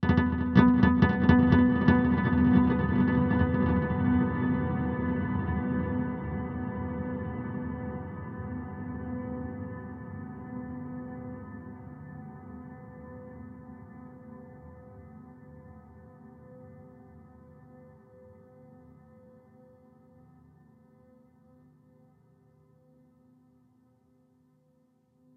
Below an example of how a simple pizzicato sound can be granularized recursively :
And the resulting sound, with no reverb added at any stage :